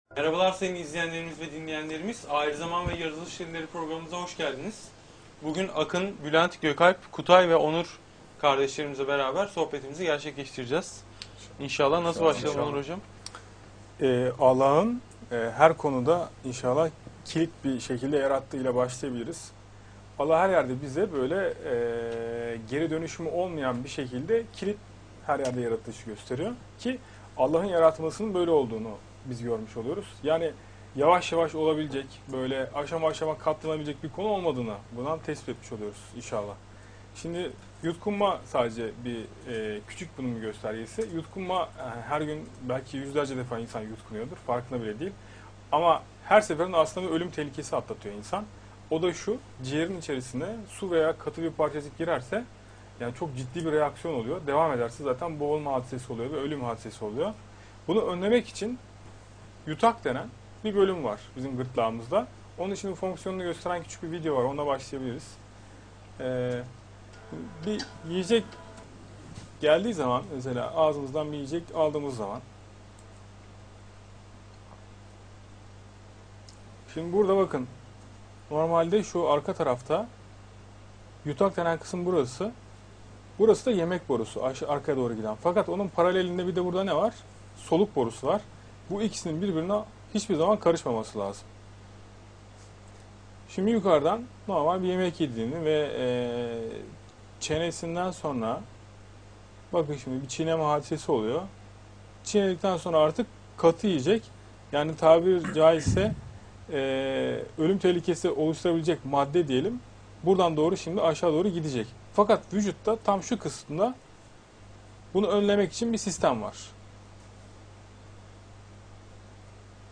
A9 TV'deki canlı sohbeti